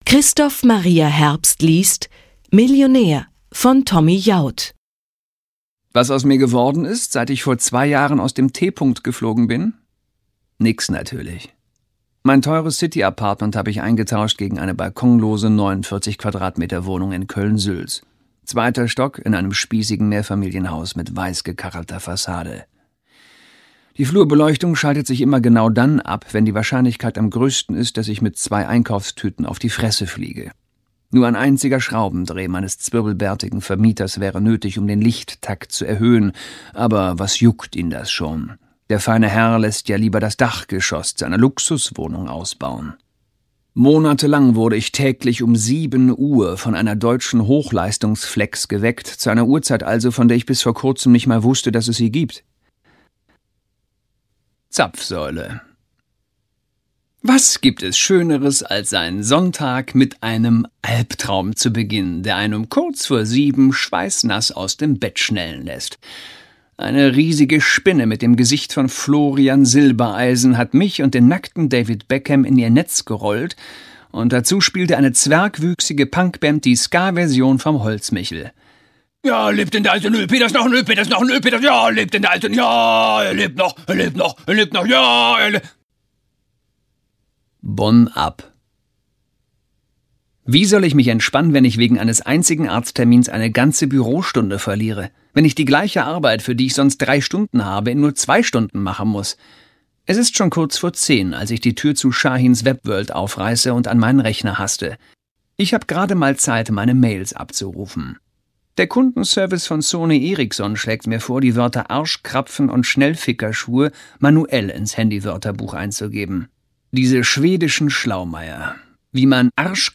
Christoph Maria Herbst (Sprecher)
2009 | 15. Auflage, Gekürzte Ausgabe